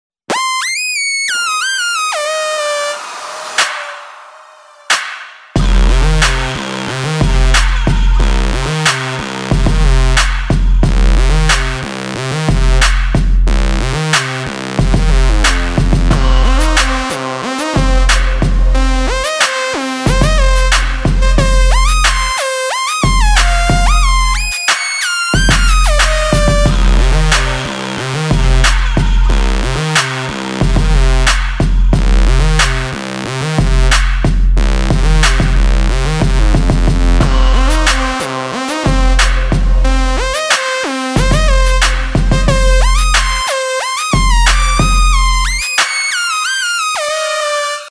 • Качество: 192, Stereo
Трэп Рингтон в отличном качестве